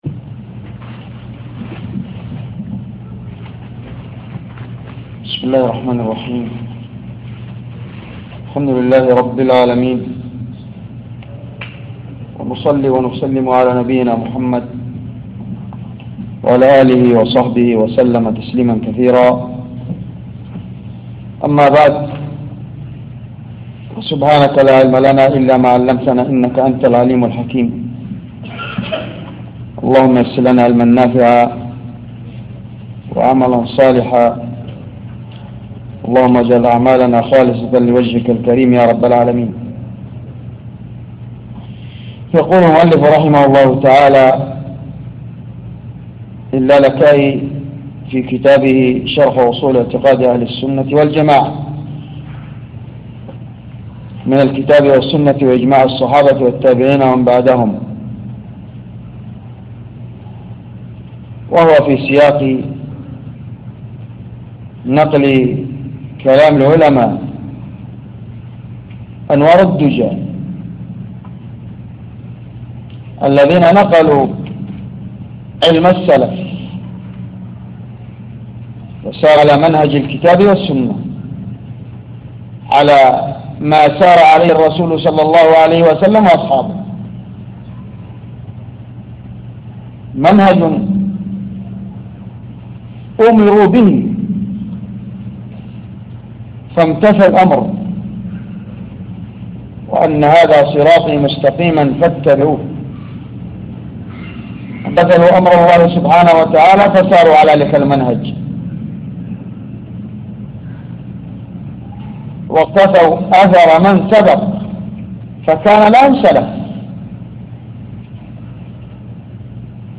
تسجيل لدروس شرح أصول اعتقاد أهل السنة والجماعة للألكائي - الجزء الثاني